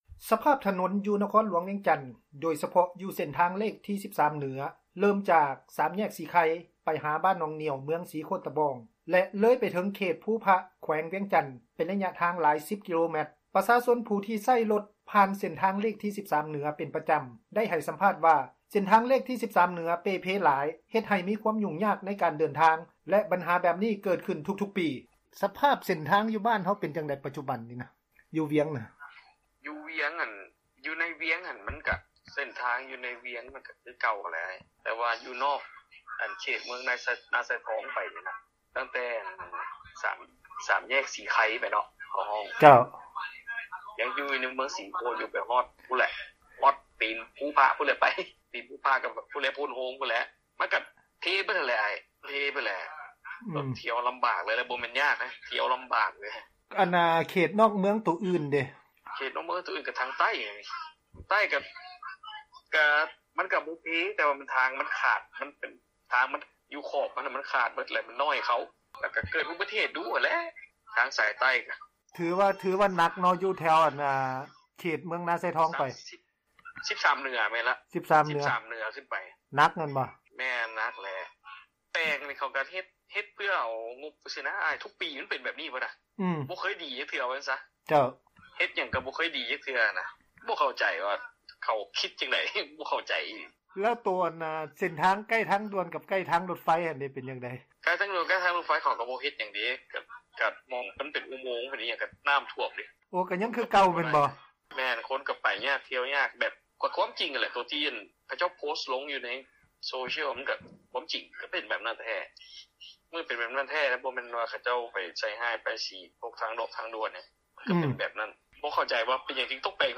ປະຊາຊົນຜູ້ທີ່ໃຊ້ຣົດຜ່ານເສັ້ນທາງເລກ 13 ເໜືອ ເປັນປະຈຳໃຫ້ສັມພາດ ວ່າ: ເສັ້ນທາງເລກທີ 13 ເໜືອເປ່ເພຫຼາຍເຮັດໃຫ້ມີຄວາມຫຍຸ້ງ ຍາກໃນການເດີນທາງ ແລະ ບັນຫາແບບນີ້ ເກີດຂຶ້ນທຸກໆປີ.